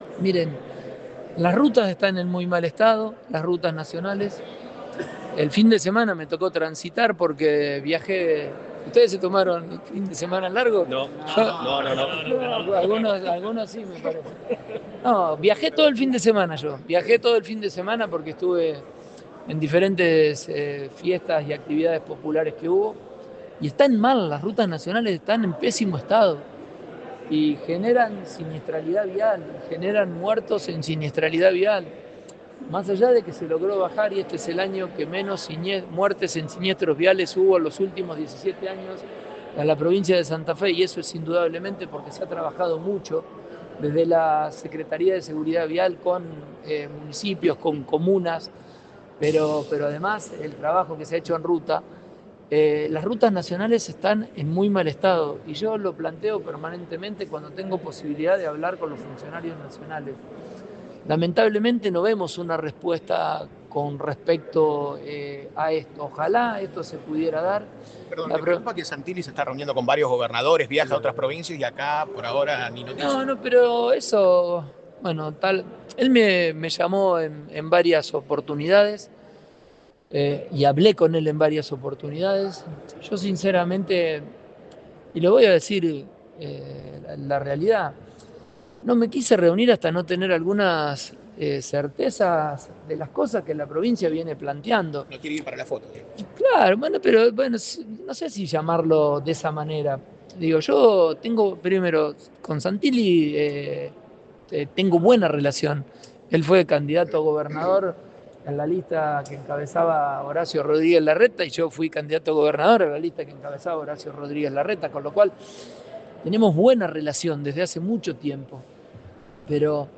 Durante una conferencia de prensa brindada este miércoles 26 de noviembre, Pullaro advirtió que el Ejecutivo nacional aún no ha dado señales claras sobre problemas centrales como el deterioro de las rutas nacionales.